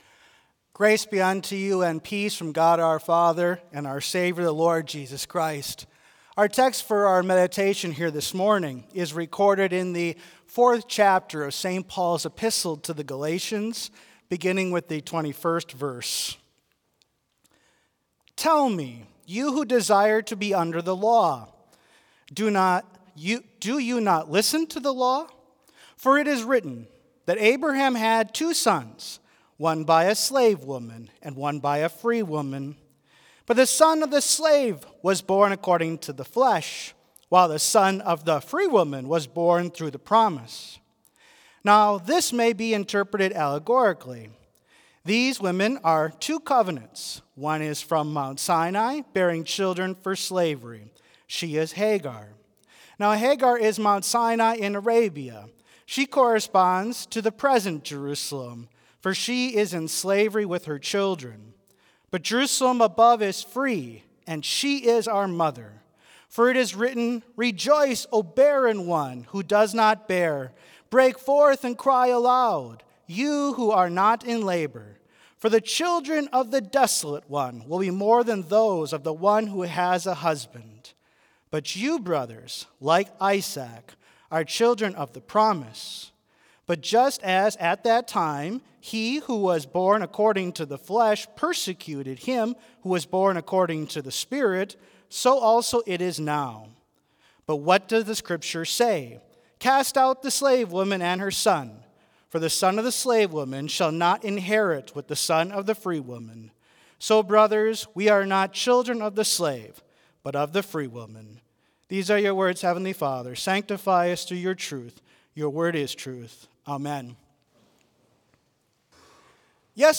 Complete service audio for Chapel - Monday, March 31, 2025